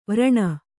♪ vraṇa